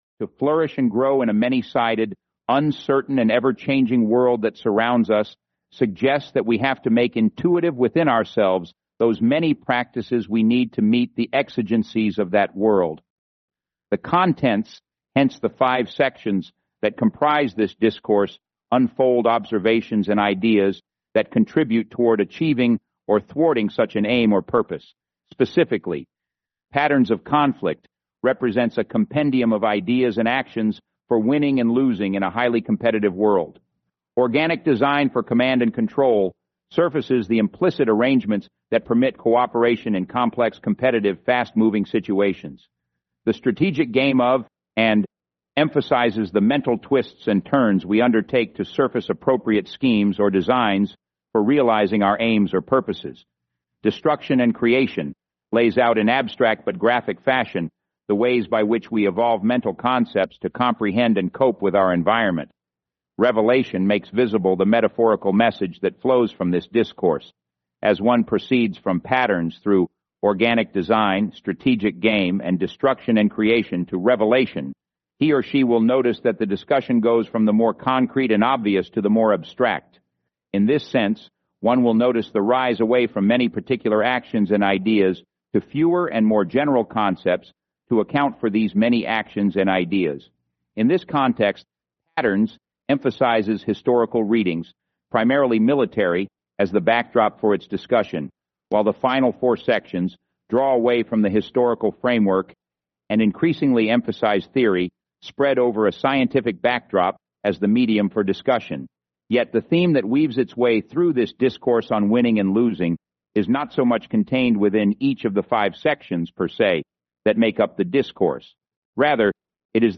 Audio (AI Generated based on official, publicly available recordings of Ret. Col. John Boyd)